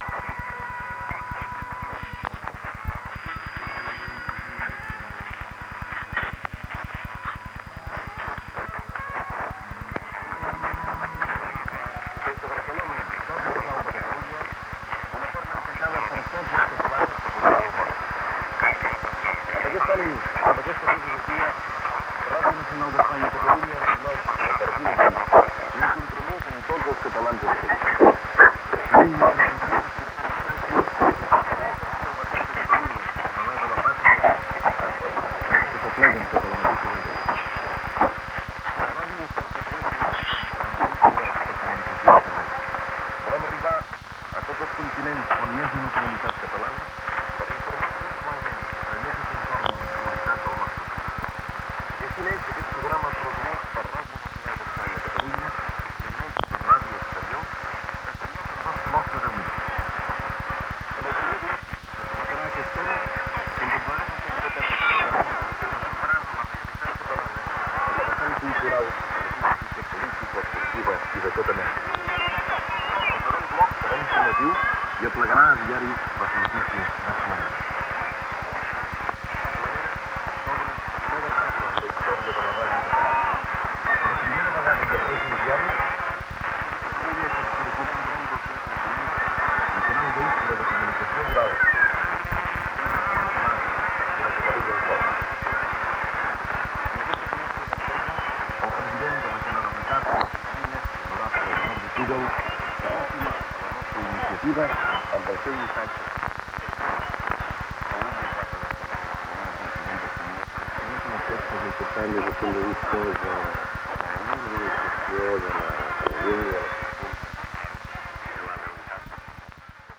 Enregistrament a la banda d'ona curta fet des de Barcelona.